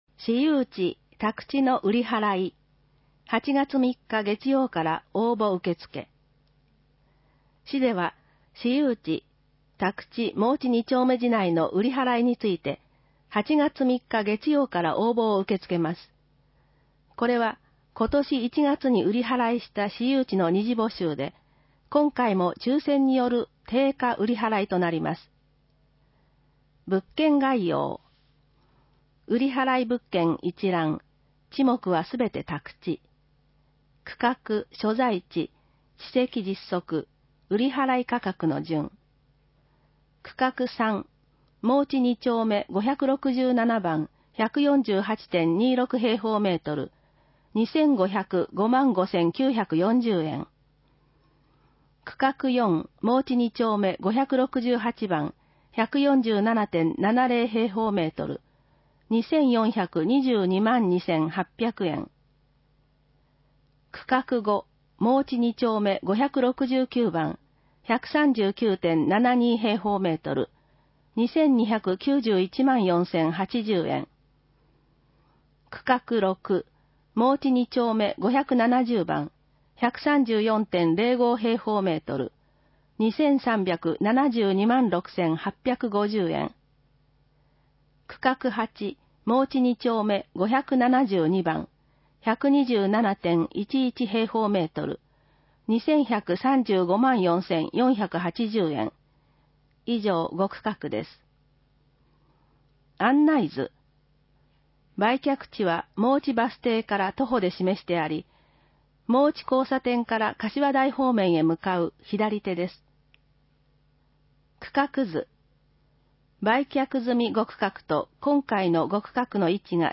音声版は、音声訳ボランティア「矢ぐるまの会」のご協力により、同会が視覚障がい者の方のために作成したものを登載しています